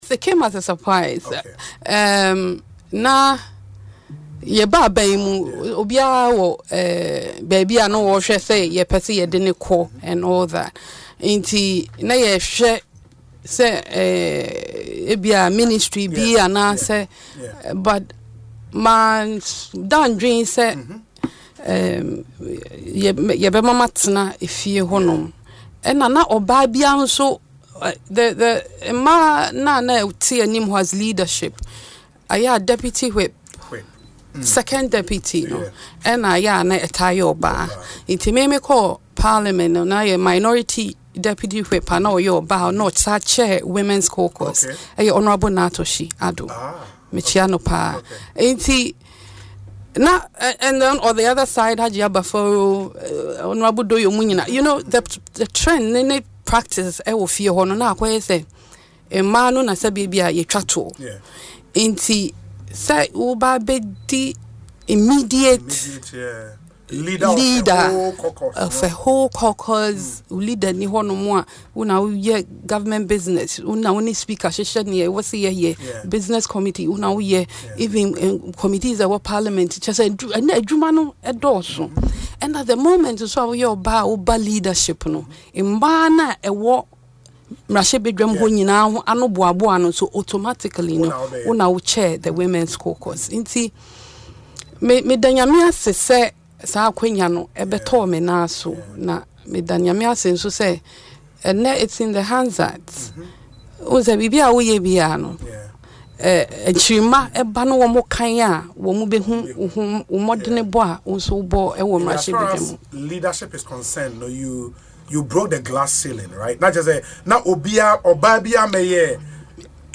Speaking in an interview on Asempa FM’s Ekosii Sen show, she recounted that she did not expect to hold such a position when the New Patriotic Party (NPP) came to power in 2016.